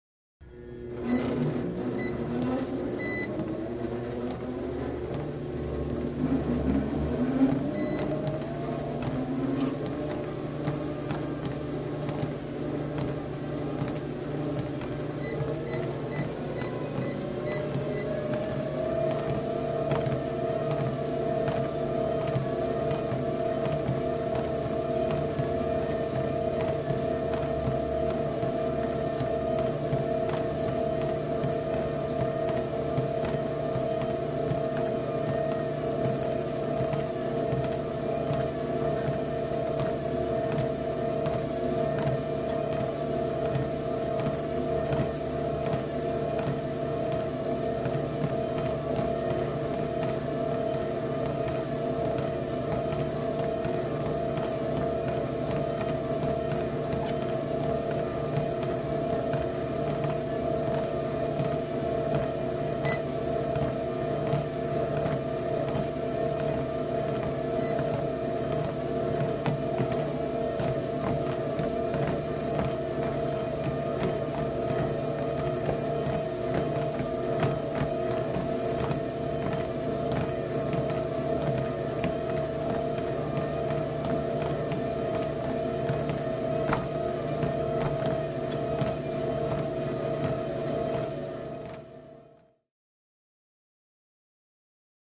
TreadmillStartJog WES030301
Cardiovascular Exercise; Treadmills Start Up, From Walking To Jogging. Two Joggers.